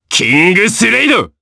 Ezekiel-Vox_Kingsraid_jp.wav